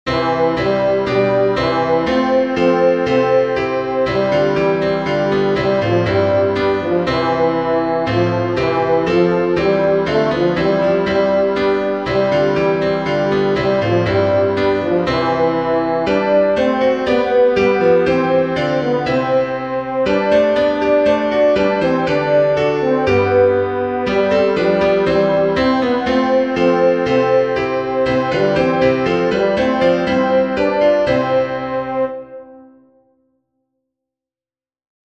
Tenor
easter_jesus_christ_is_risen_today-tenor.mp3